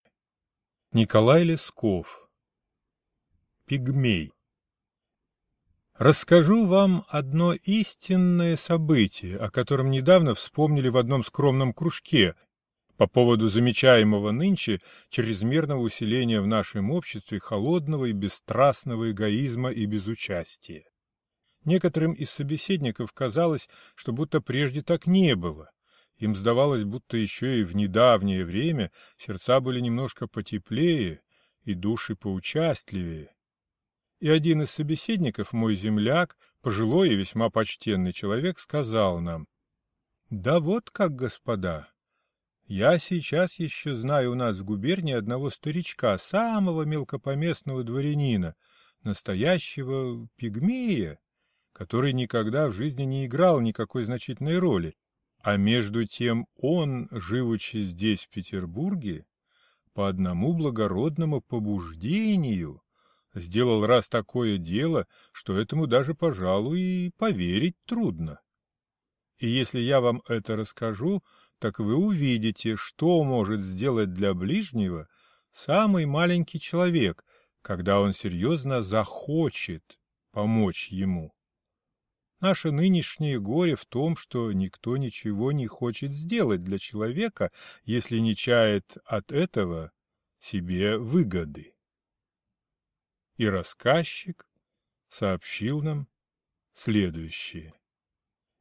Аудиокнига Пигмей | Библиотека аудиокниг